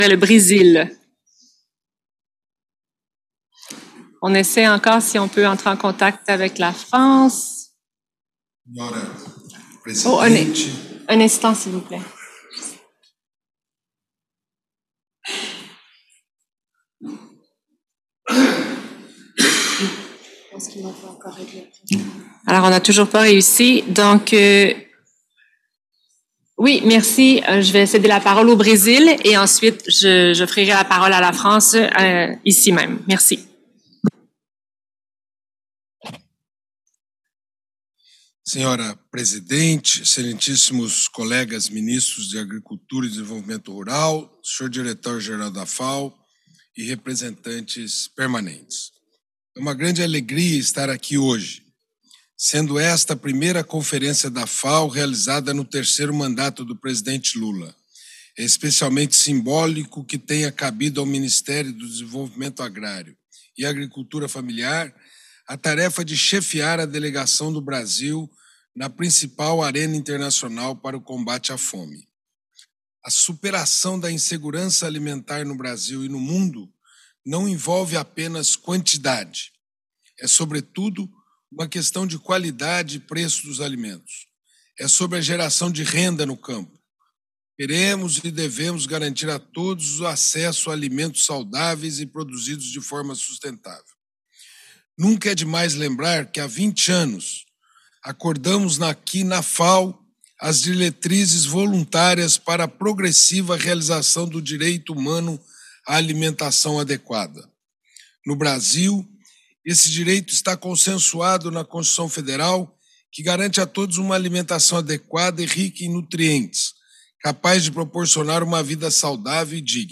GENERAL DEBATE
Addresses and Statements
H.E. Paulo Teixeira, Minister for Rural Development and Family Farming
(Plenary – Portuguese)